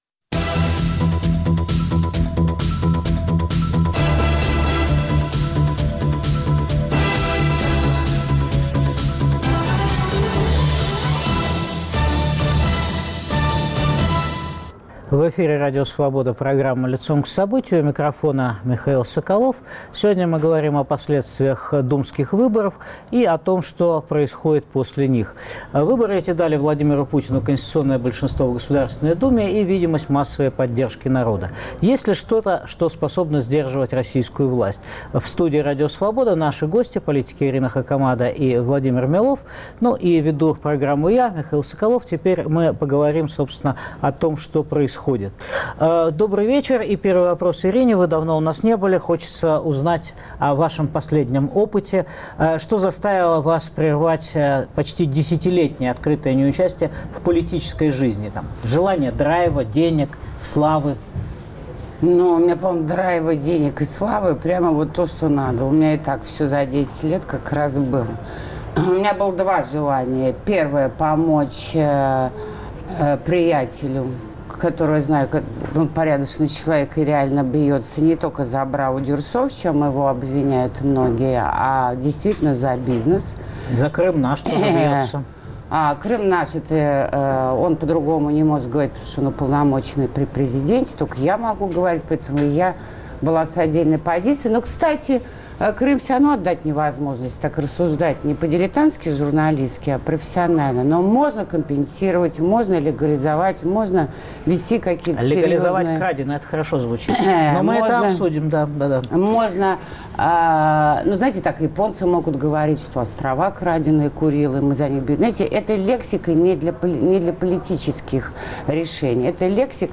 Об оппозиции в тоталитарном государстве Путина спорят политики Ирина Хакамада и Владимир Милов.